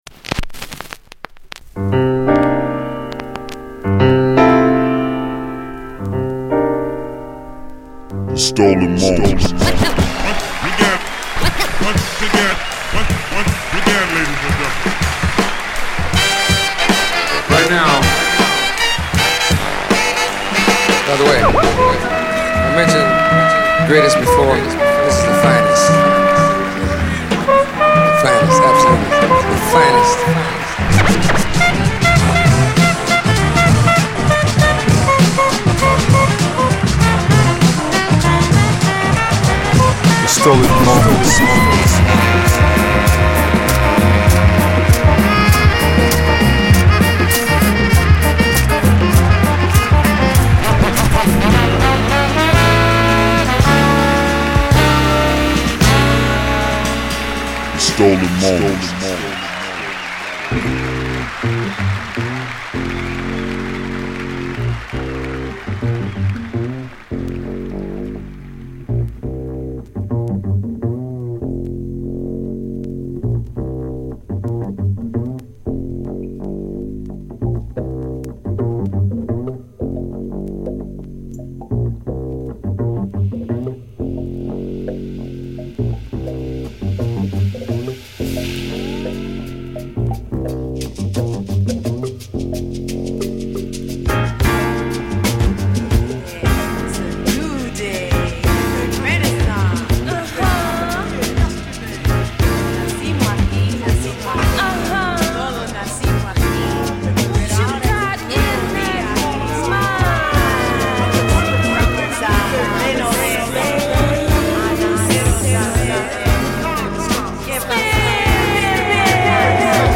※試聴はダイジェストです。
Mellow Groove , Mix CD